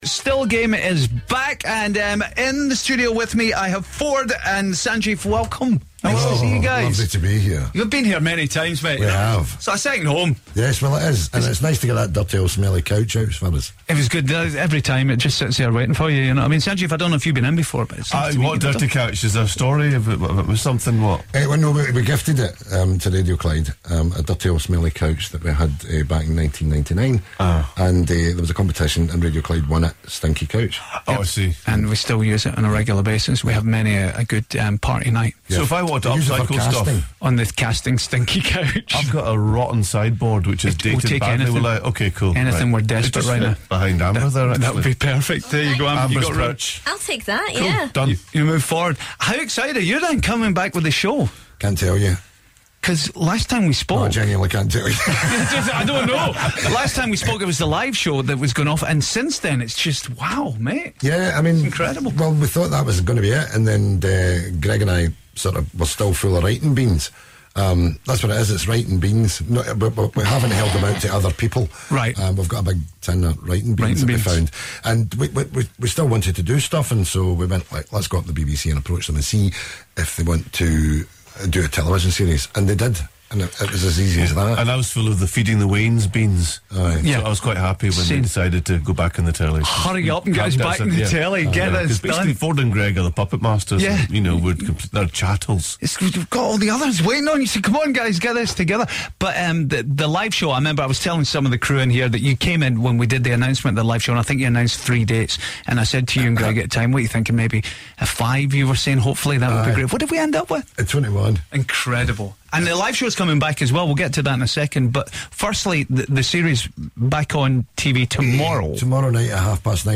chats to Ford Kiernan & Sanjeev Kohli from Still Game ahead of the new TV series on BBC 1